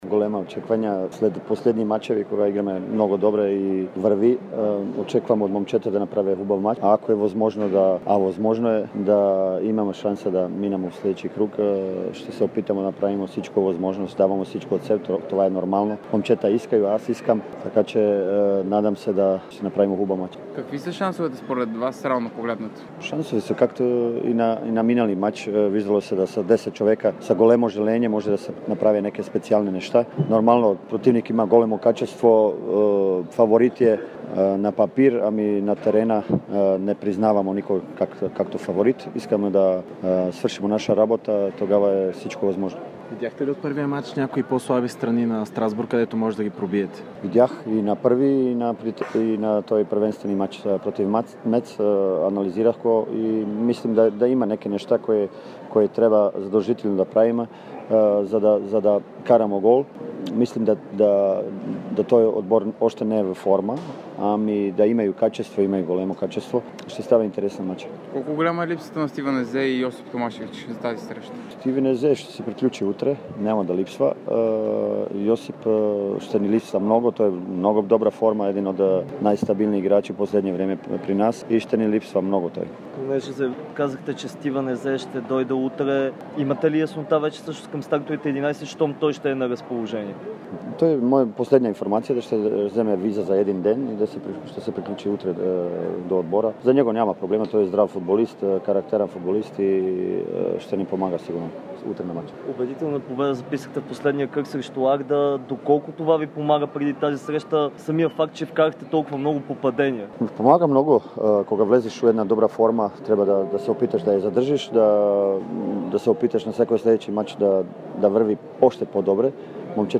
Чуйте цялото изявление на босненския специалист в аудио секцията.